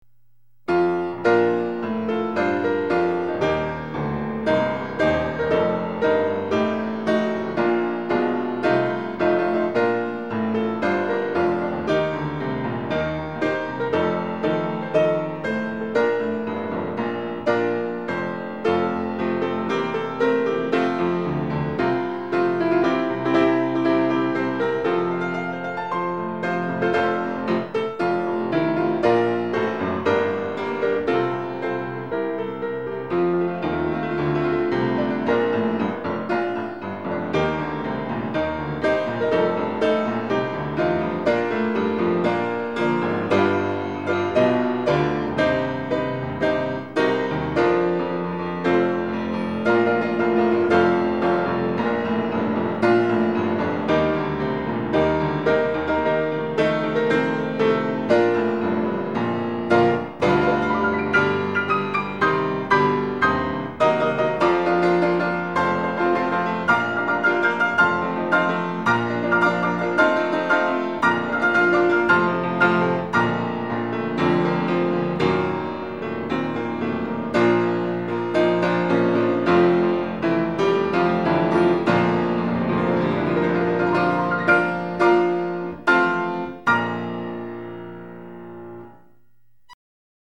Саундтрак очень хорош; к сожалению, непонятно, какого происхождения.
таперская интерпретация Интернационала.